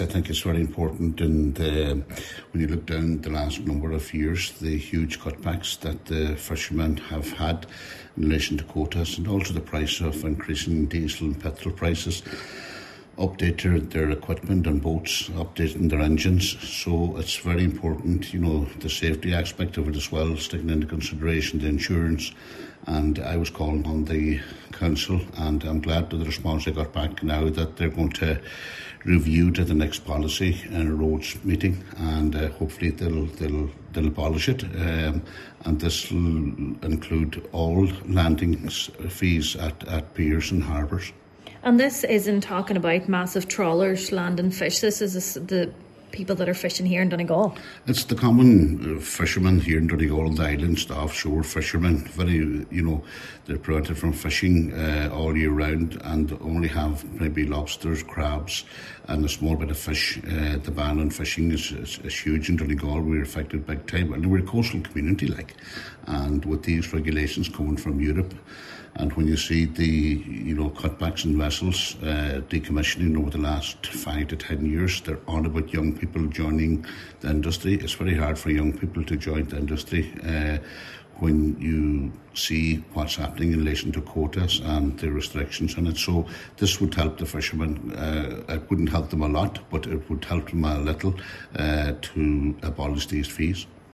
Councillor Ó Fearraigh says intervention, no matter how small would go a long way in helping the fishing industry survive: